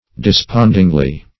despondingly - definition of despondingly - synonyms, pronunciation, spelling from Free Dictionary Search Result for " despondingly" : The Collaborative International Dictionary of English v.0.48: Despondingly \De*spond"ing*ly\, adv.